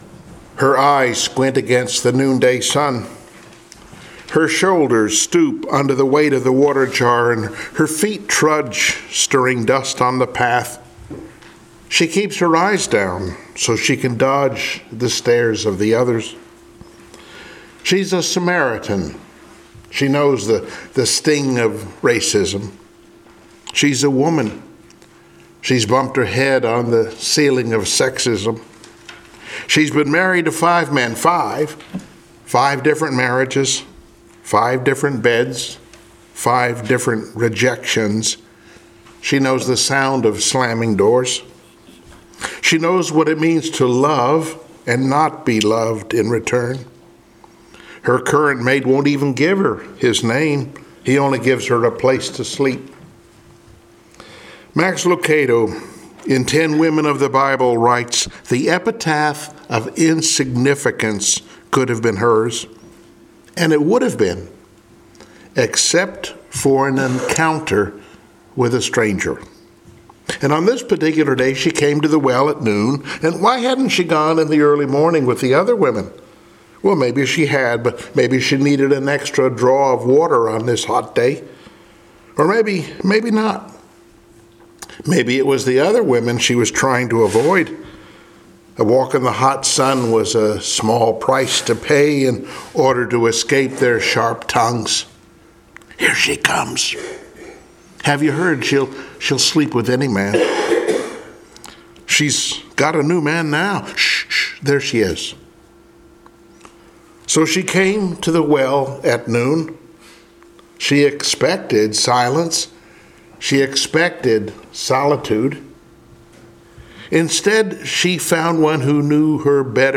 Passage: John 4:1-42 Service Type: Sunday Morning Worship